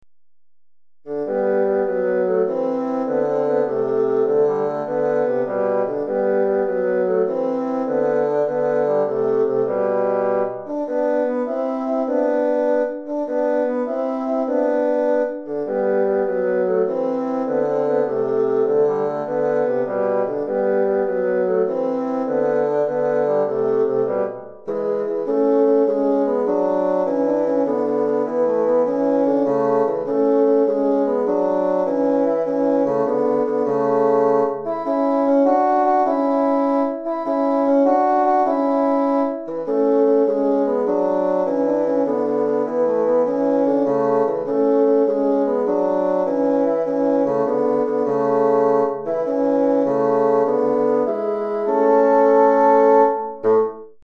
2 Bassons